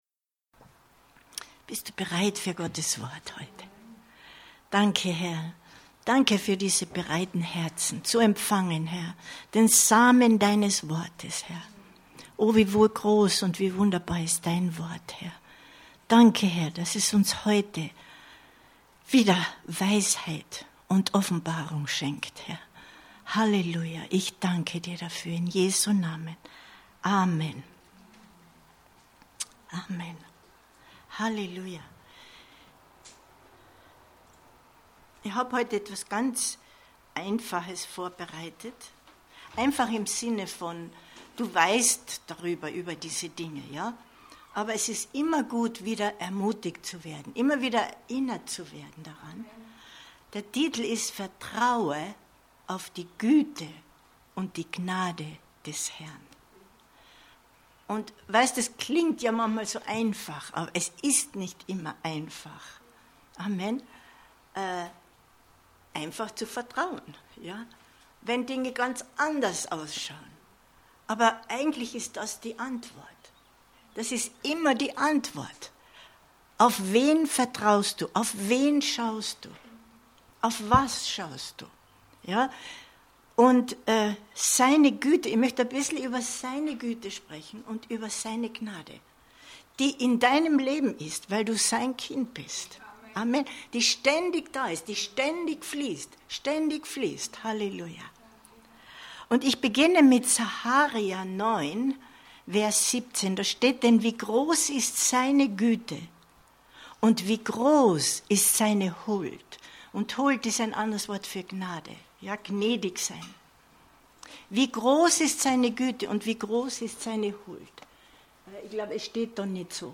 Vertraue der Güte und Gnade des Herrn 26.06.2022 Predigt herunterladen